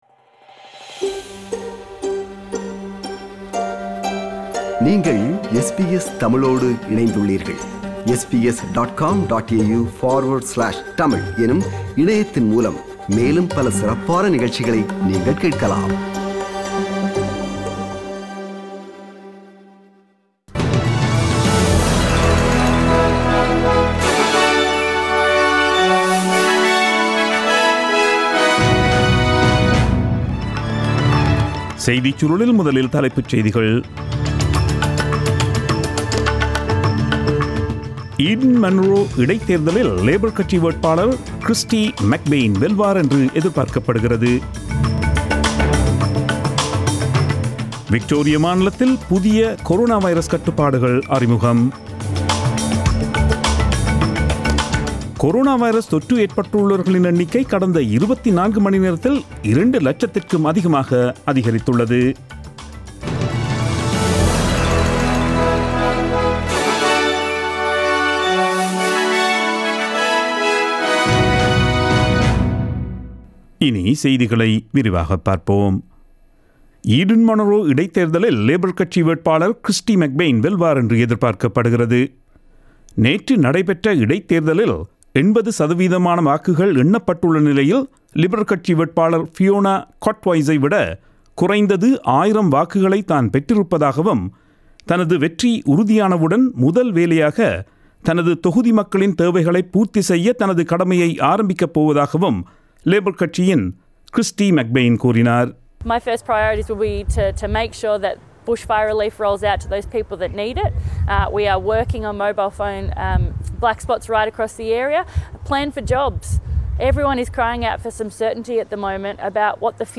Australian news bulletin aired on Sunday 05 July 2020 at 8pm.